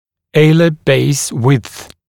[‘eɪlə beɪs wɪdθ][‘эйлэ бэйс уидс]ширина крыльев носа